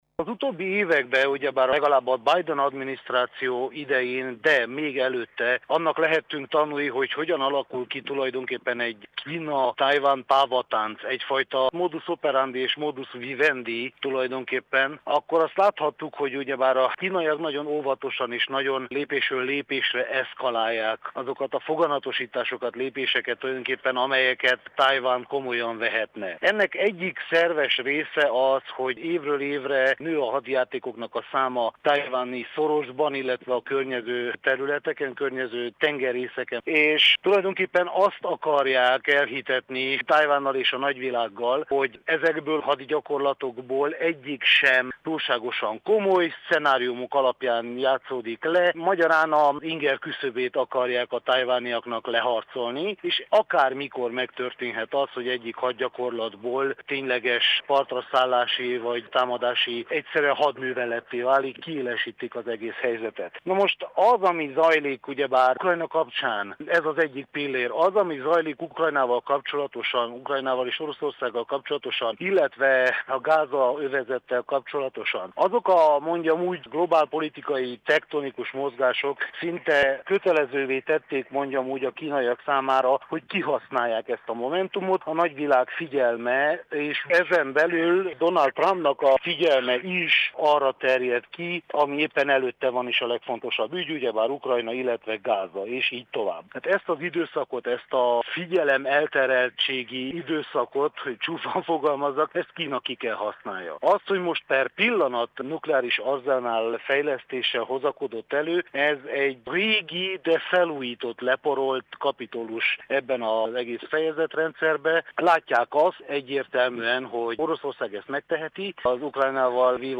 külpolitikai elemző